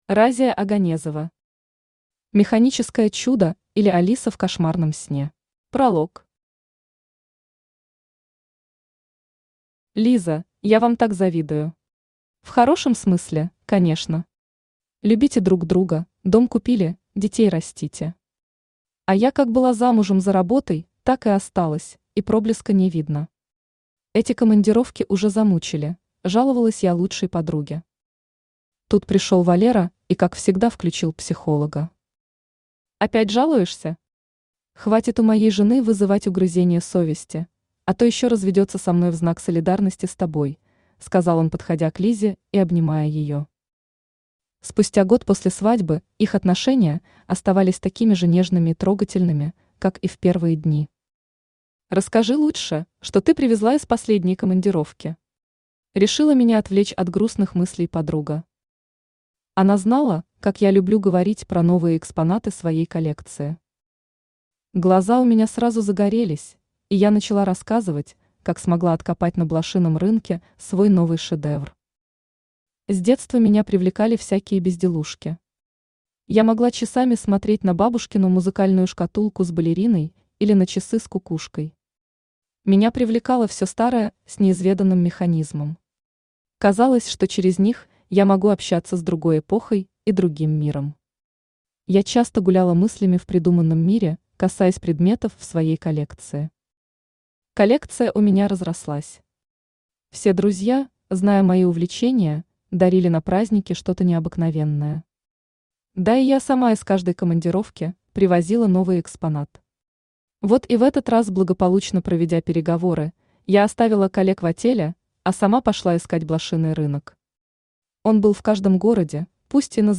Аудиокнига Механическое чудо, или Алиса в кошмарном сне | Библиотека аудиокниг
Aудиокнига Механическое чудо, или Алиса в кошмарном сне Автор Разия Оганезова Читает аудиокнигу Авточтец ЛитРес.